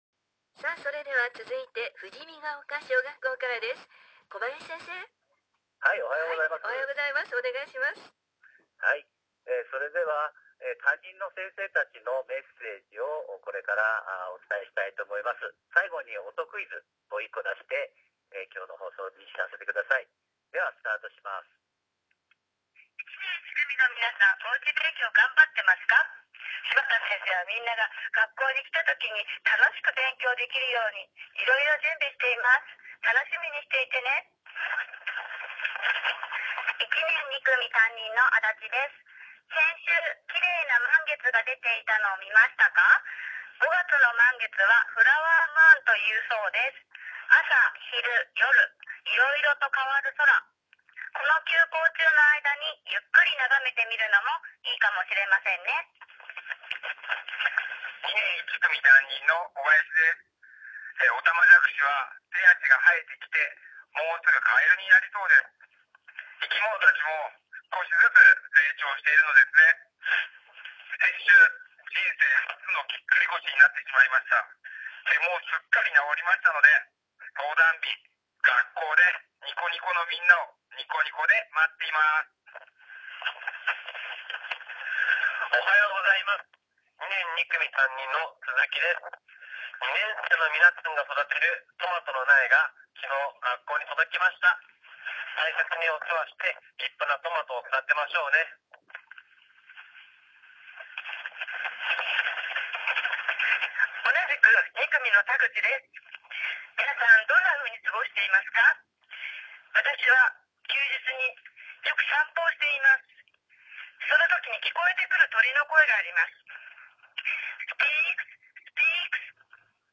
先生方が学校図書館に集まり、何をしているかというと、来週のＦＭたちかわの録音取りです。けっこう緊張している様子でした。次回が最終回ということで、担任の先生の声が再び登場します。